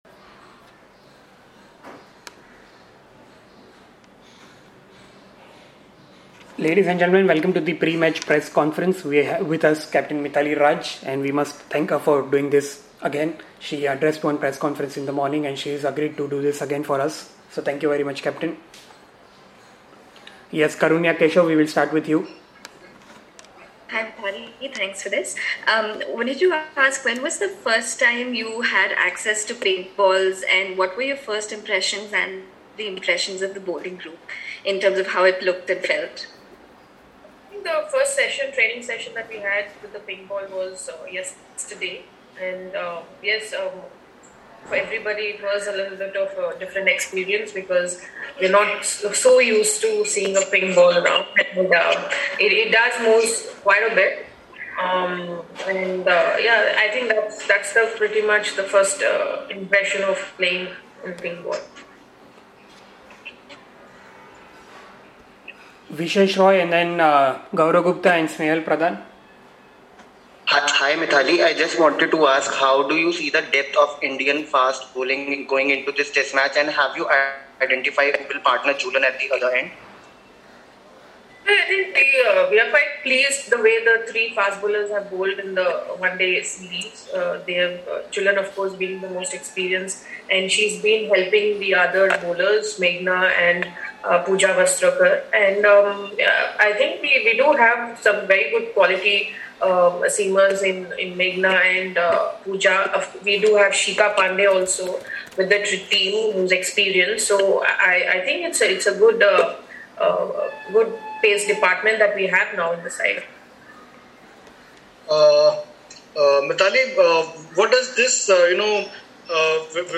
Mithali Raj addressed a virtual press conference ahead of the historic pink-ball Test to be played against Australia from Thursday at Metricon Stadium, Carrara.